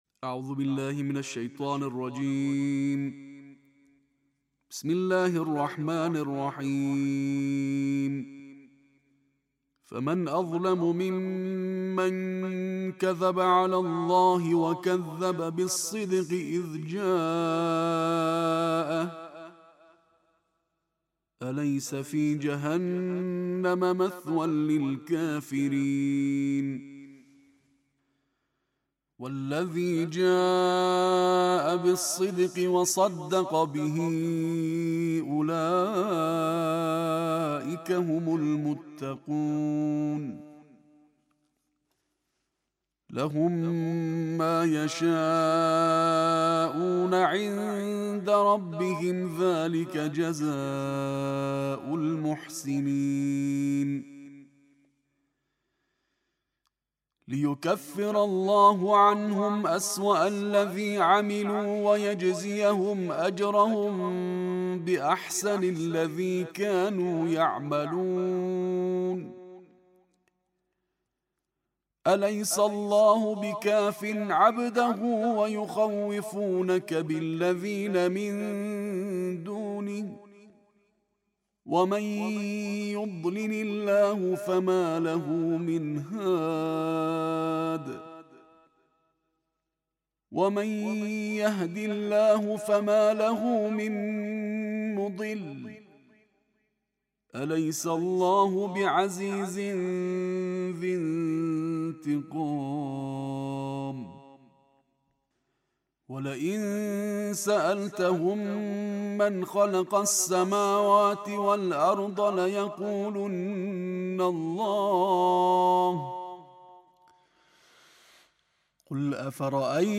Everyday with Quran: Tarteel Recitation of Juz 24